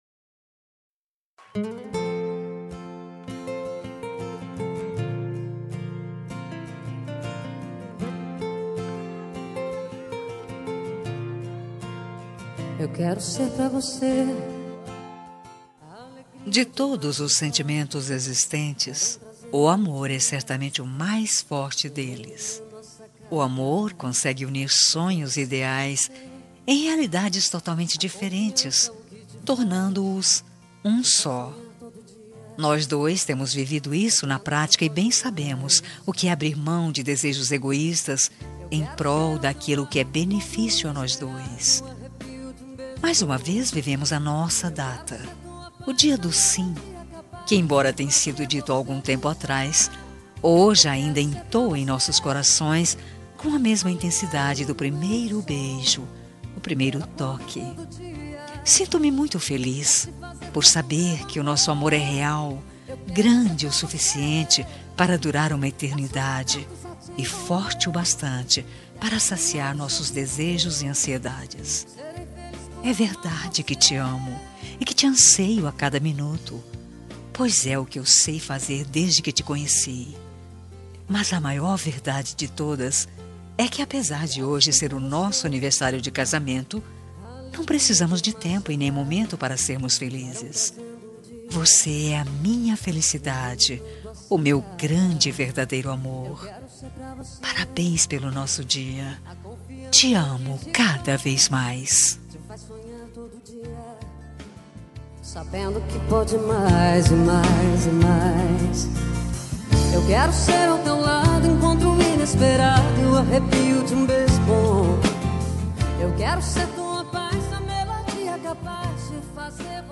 Aniversário de Casamento Romântica – Voz Feminina – Cód: 1293 – Linda
1293-aniv-cas-fem.m4a